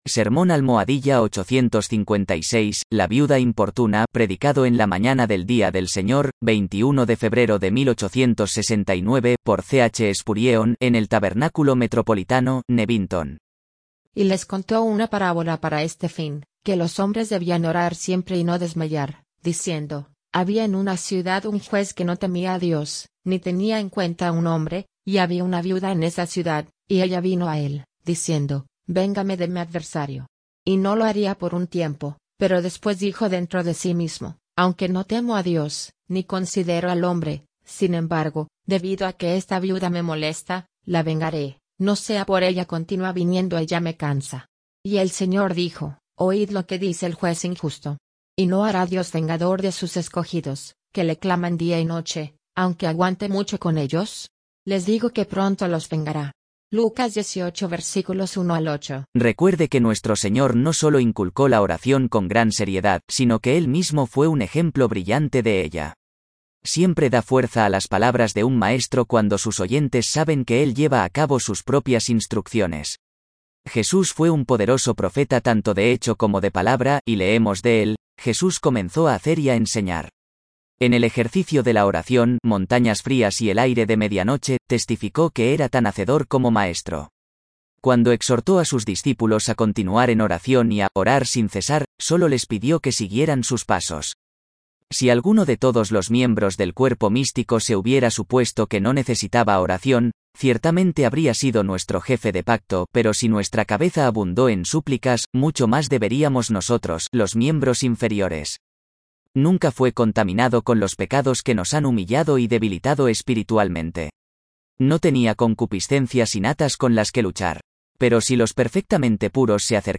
PREDICADO LA MAÑANA DEL DOMINGO 21 DE FEBRERO DE 1869 POR C. H. SPURGEON,
EN EL TABERNÁCULO METROPOLITANO, NEWINGTON.